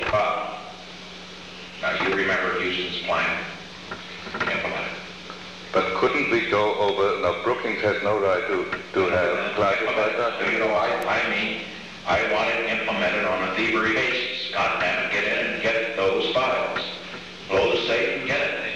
In response, Nixon ordered a clandestine team to clean out the Brookings safe and retrieve the files. Date: June 17, 1971 Location: Oval Office Tape Number: 525-001 Participants Richard M. Nixon Henry A. Kissinger Associated Resources Annotated Transcript Audio File Transcript